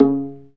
Index of /90_sSampleCDs/Roland - String Master Series/STR_Viola Solo/STR_Vla Pizz